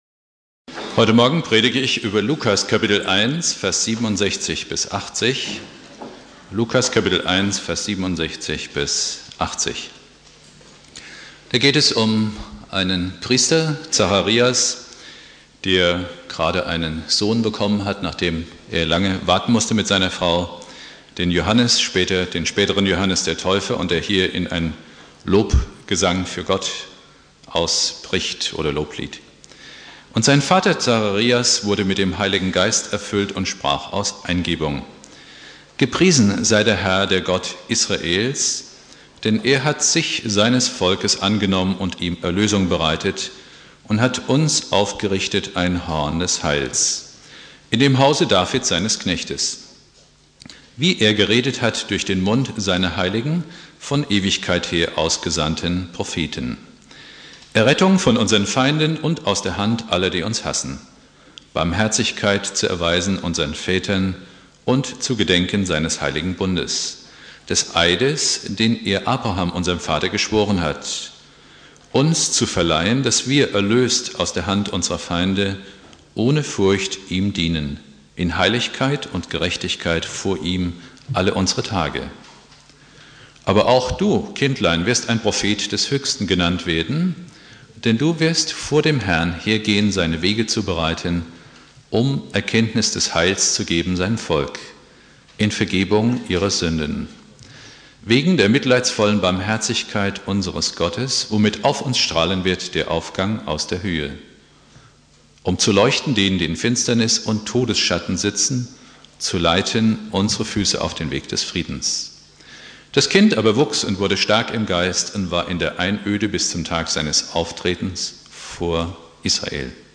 Predigt
1.Advent Prediger